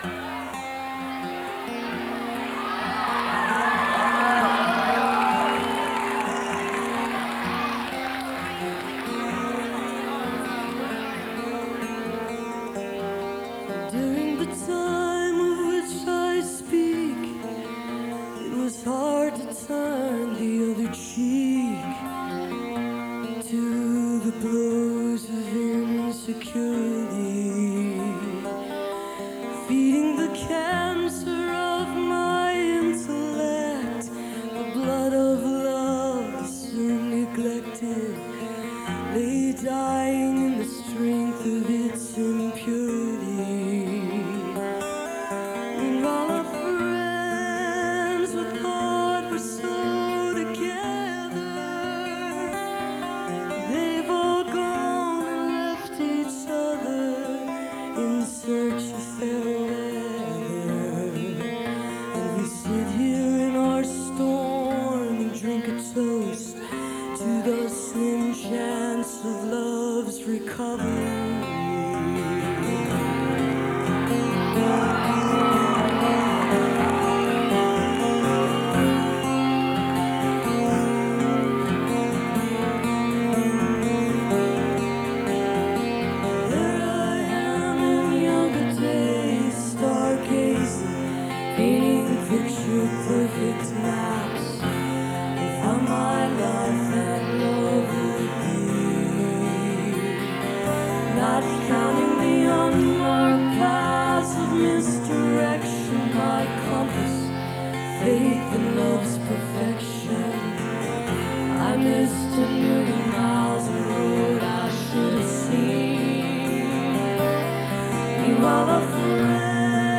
(radio broadcast source)